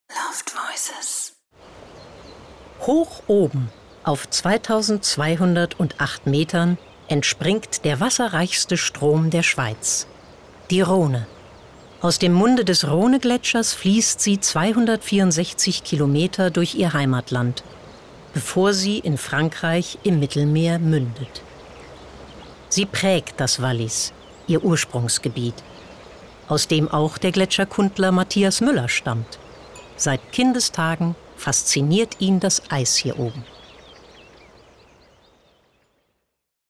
markant
Norddeutsch
Doku